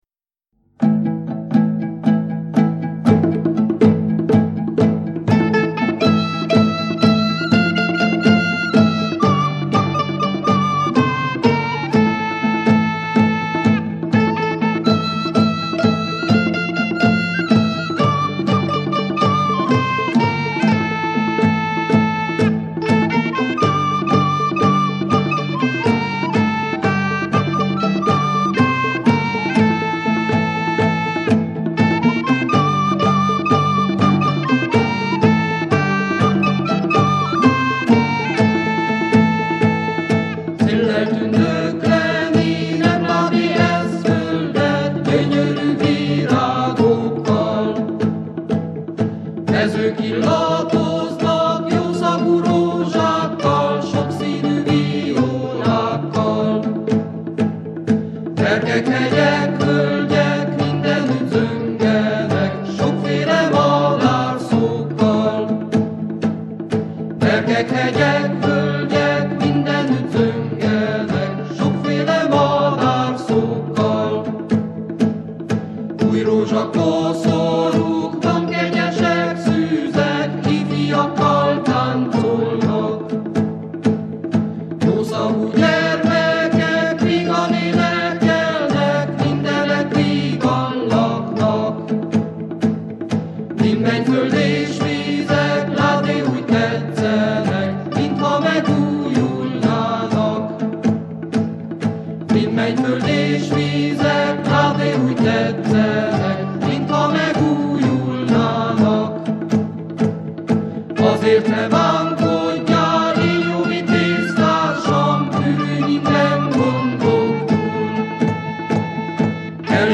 A lemezen históriás énekek, széphistóriák, vándorénekek, Balassi énekelt versei, népies műfajok, virágénekek, kuruc dalok, táncdallamok találhatók…” (1985)
Itt hallható a lemez címadó darabja, amelynek szövege alább olvasható és megtalálható az ünnepi kiadványban is: